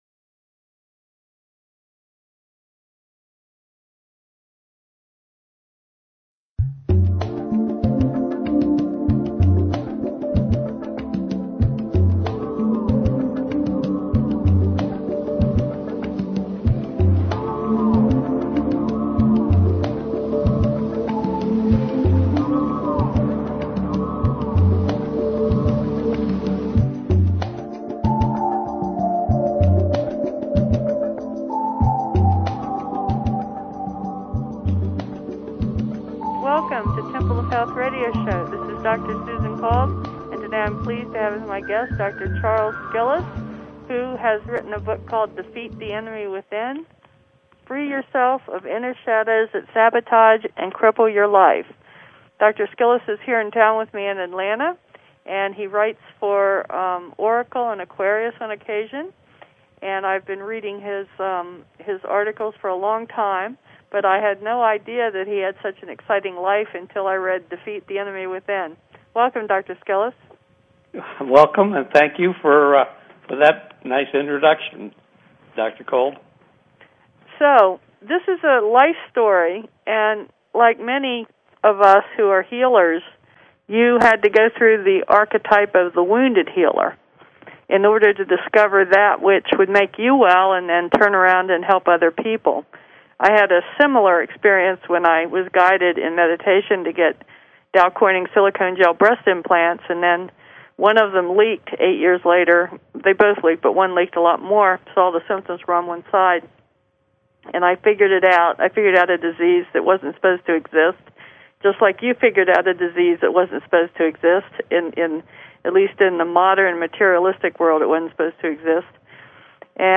Temple of Health Radio Show